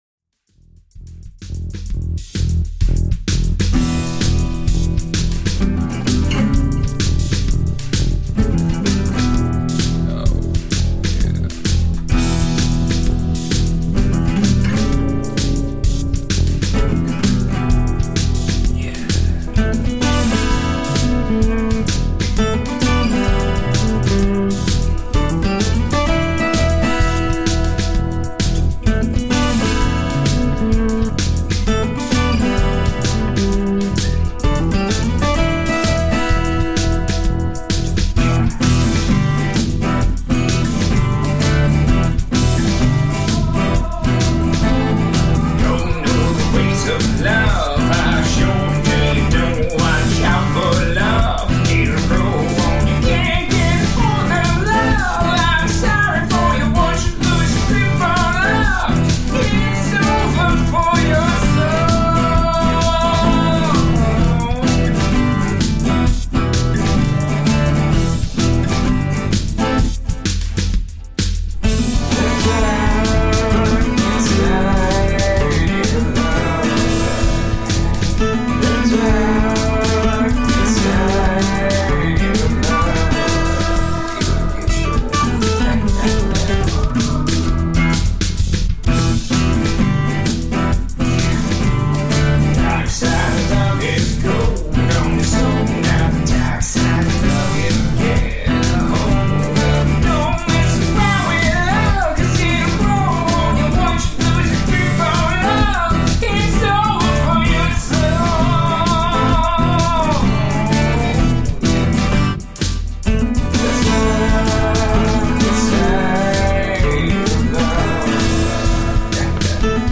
Introspective, melodic rock.
Tagged as: Alt Rock, Other